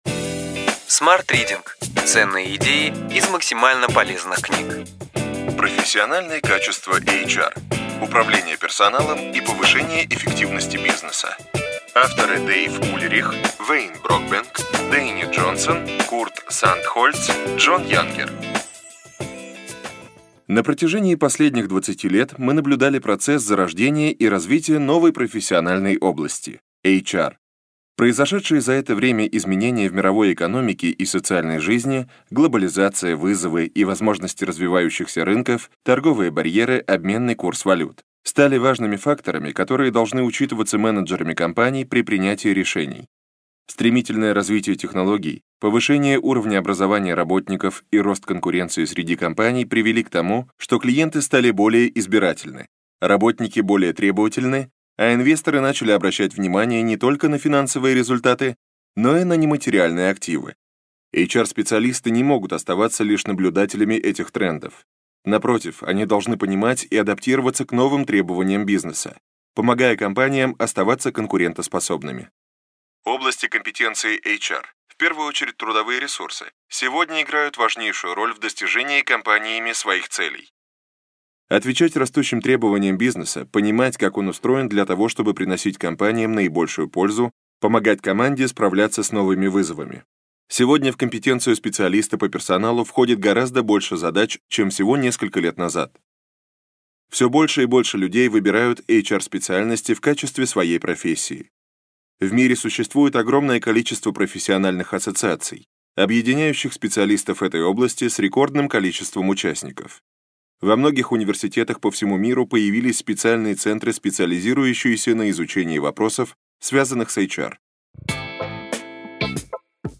Аудиокнига Ключевые идеи книги: Профессиональные качества HR: управление персоналом и повышение эффективности бизнеса.